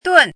怎么读
dùn
dun4.mp3